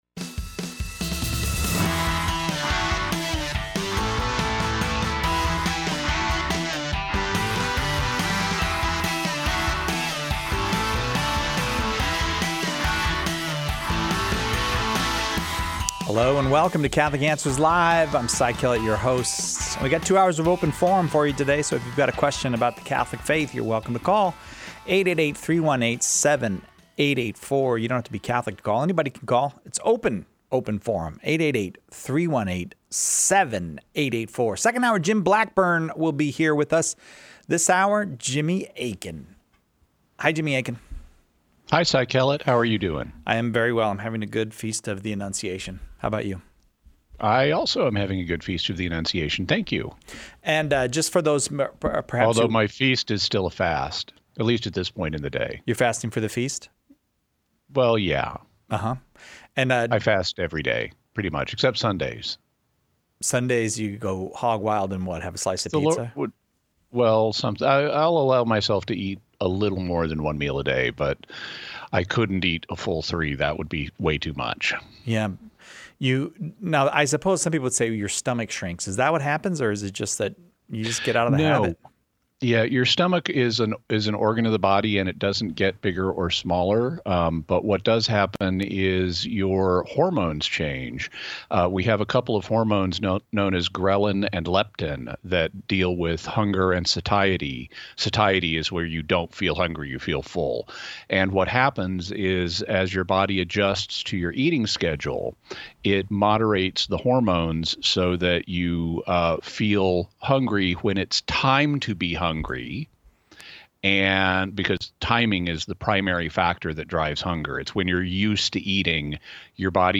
Listeners call in with their questions about the Catholic faith.